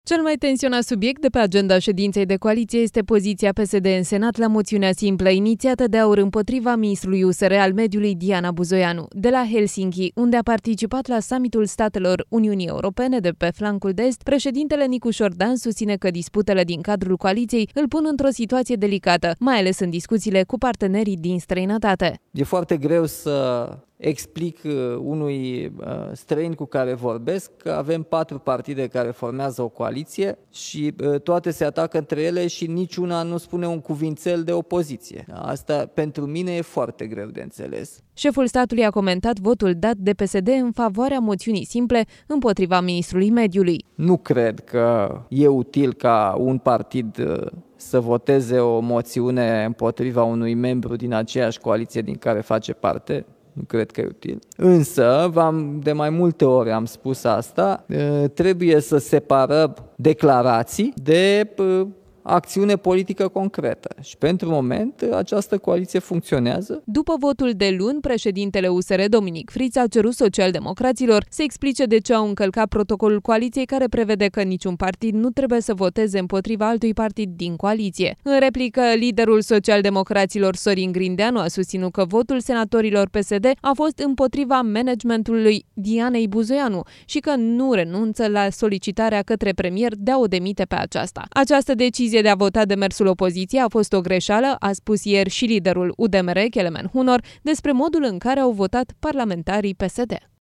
De la Helsinki, unde a participat la Summitul statelor UE de pe Flancul de Est, președintele Nicușor Dan susține că disputele din cadrul coaliției îl pun într-o situație delicată, mai ales în discuțiile cu partenerii din străinătate.
17dec-07-July-SNDW-sedinta-coalitie-reactia-lui-Nicusor.mp3